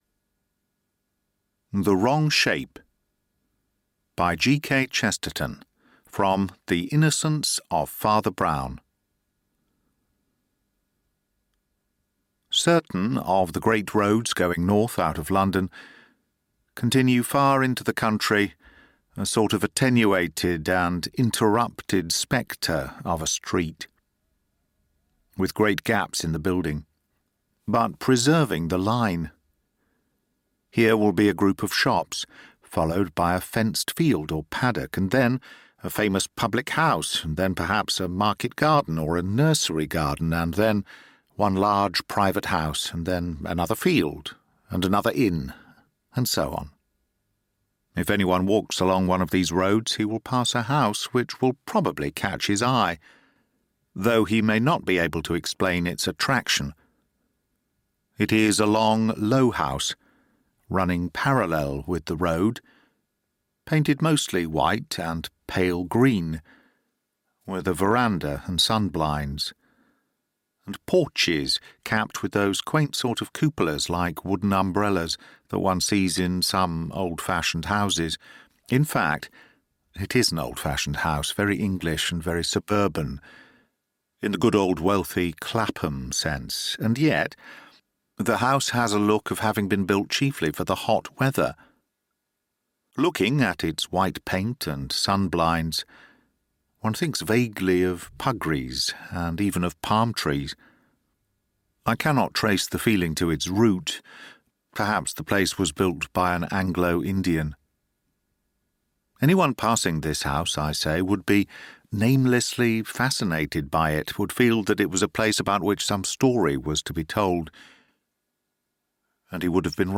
The Golden Pince-Nez: Deadly Secrets Uncovered (Audiobook)